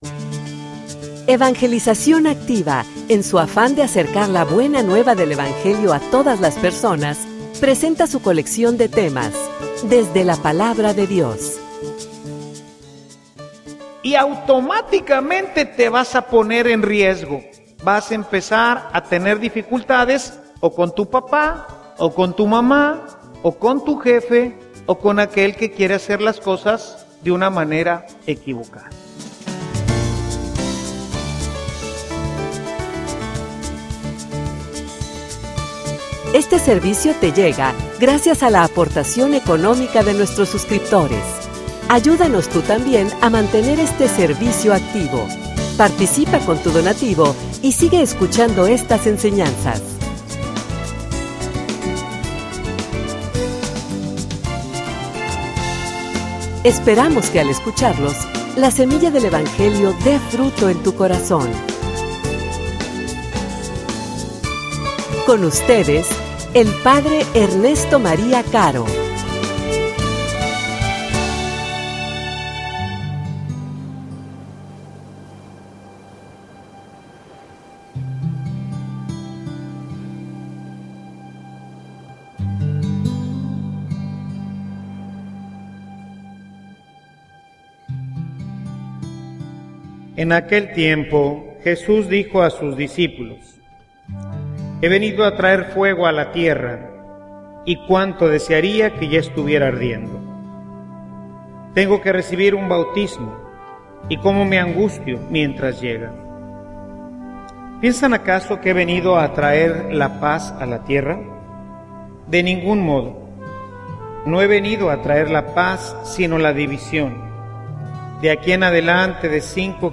homilia_Las_consecuencias_del_discipulado.mp3